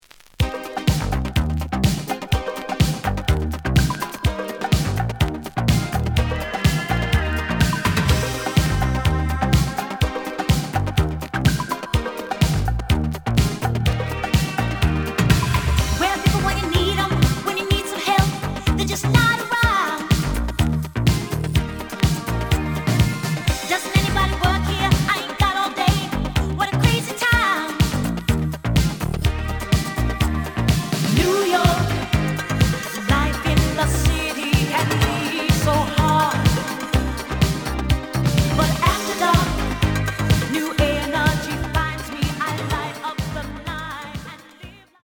The audio sample is recorded from the actual item.
●Genre: Disco
A side plays good.